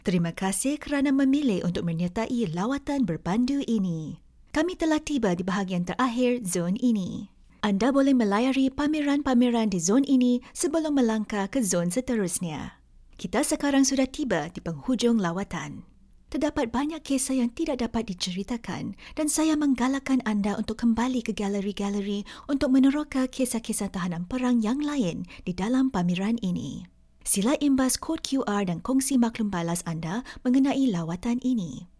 Guias de áudio
~ Uma dubladora calorosa, amigável e versátil com apelo global ~
Microfone Rode NT1 (Kit de estúdio NT1 AI-1 com interface de áudio), suporte antichoque SM6, fones de ouvido Shure SRH440A Gen 2, filtro pop, escudo de isolamento, manta de amortecimento de som e placas de feltro